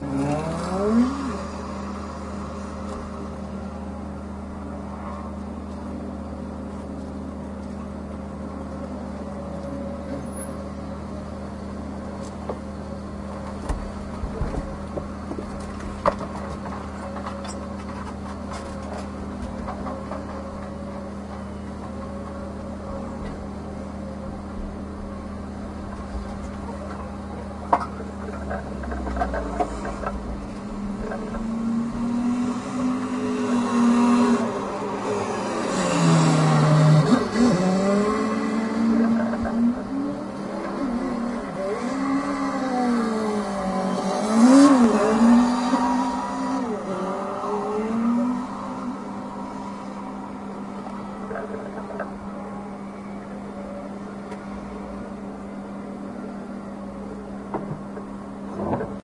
swoosh 01
描述：zoomh4n nt4
标签： Swoosh Highway Fieldrecordings
声道立体声